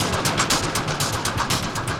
Index of /musicradar/rhythmic-inspiration-samples/120bpm
RI_DelayStack_120-03.wav